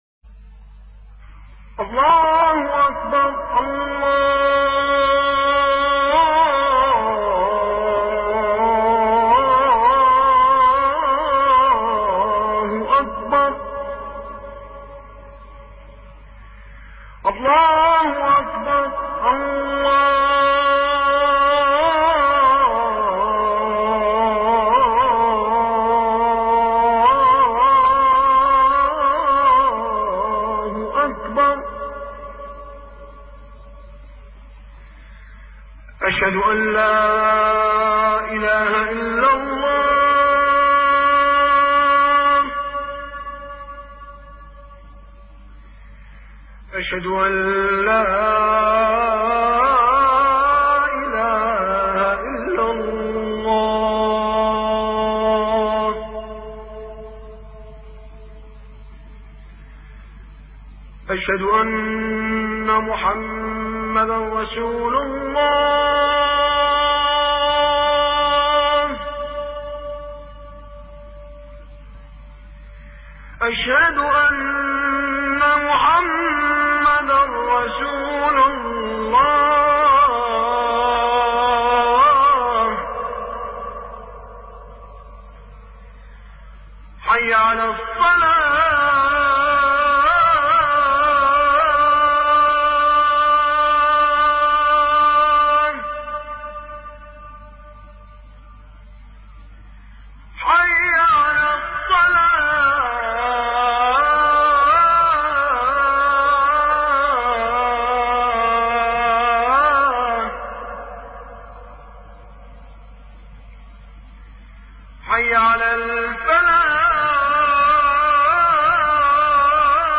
أذان الفجر - أم القوين | الإذاعات الإسلامية
أصوات الآذان